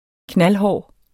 knaldhård adjektiv Bøjning -t, -e Udtale [ ˈknalˈhɒˀ ] Betydninger meget hård Synonym stenhård Februar er normalt vores allerkoldeste måned.